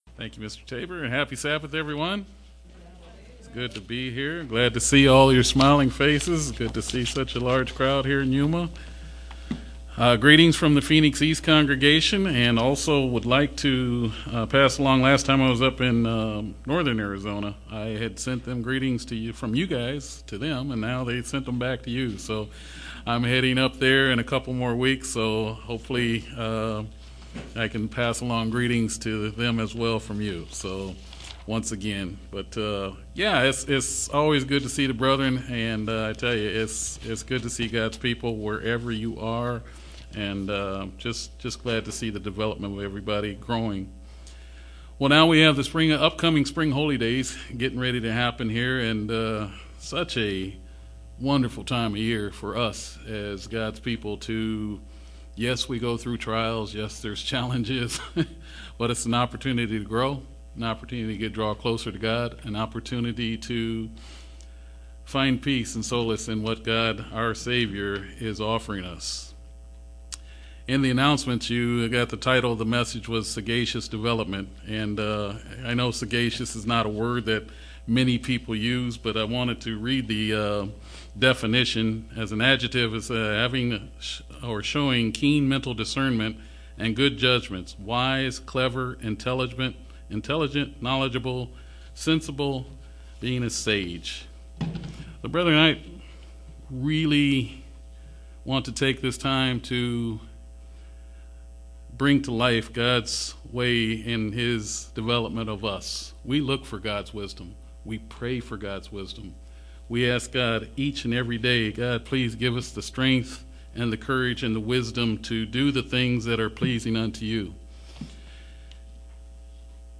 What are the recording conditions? Given in Yuma, AZ